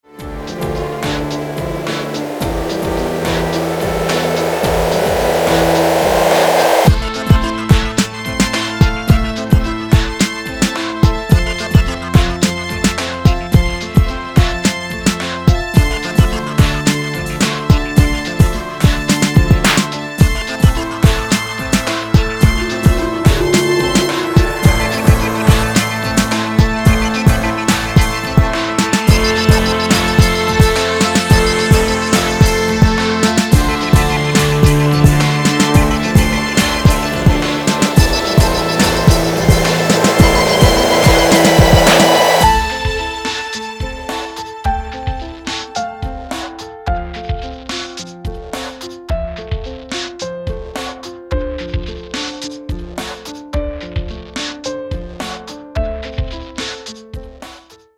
• Качество: 256, Stereo
красивые
без слов